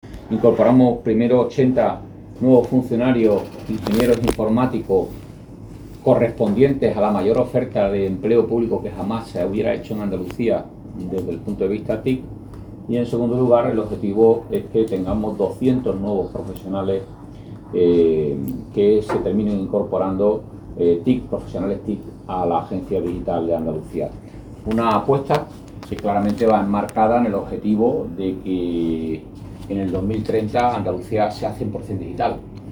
En un discurso dirigido a los nuevos funcionarios, el consejero ha resaltado que, desde su creación en 2021, el personal de la ADA no ha dejado de crecer, fruto del proceso de consolidación de recursos humanos en TIC de la Administración de la Junta de Andalucía, procedente de las consejerías, agencias administrativas y agencias de régimen especial.